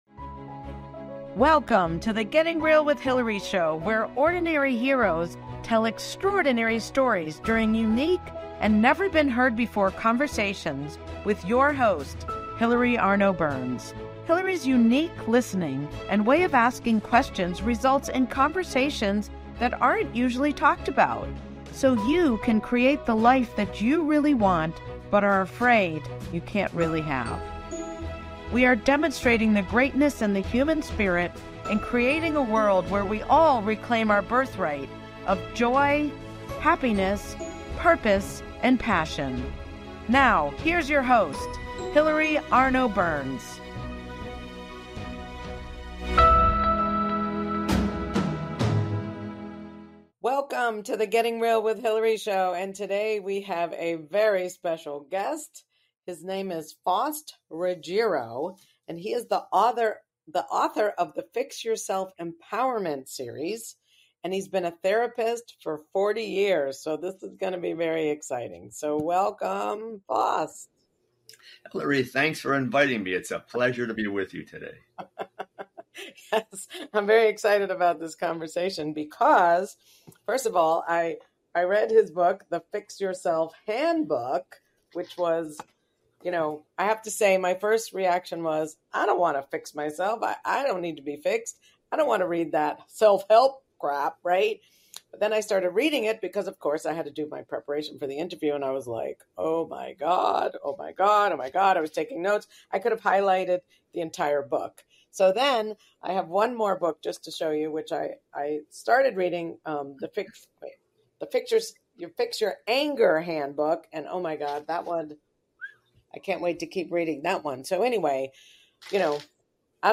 Today's interview blew my mind. People pleasing, dating, needing outside validation, healthy boundaries, being happy today, and enjoying the journey on the way to the destination were all topics we covered.